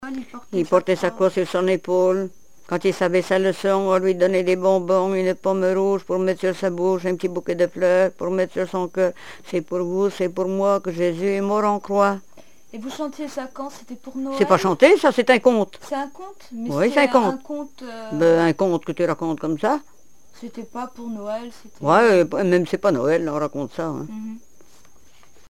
Mémoires et Patrimoines vivants - RaddO est une base de données d'archives iconographiques et sonores.
enfantine : prière, cantique
Répertoire de chansons traditionnelles et populaires
Pièce musicale inédite